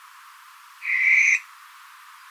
Tyto_furcata.mp3